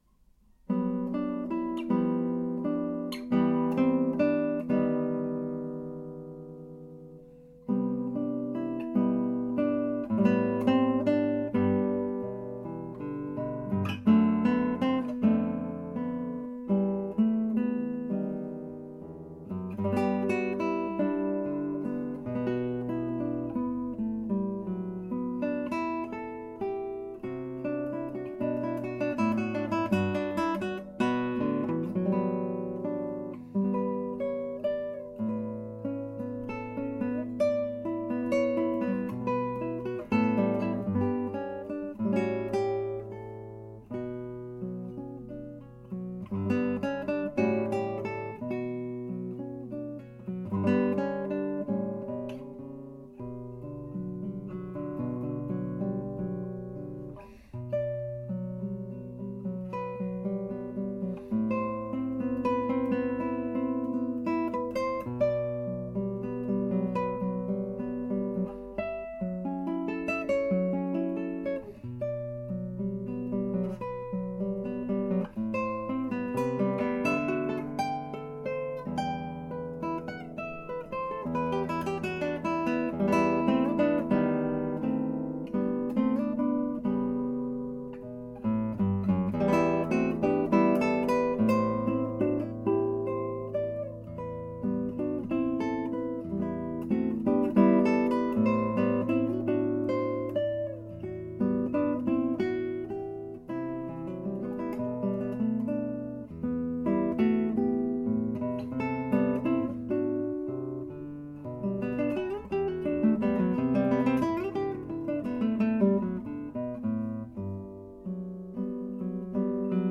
Chitarra Classica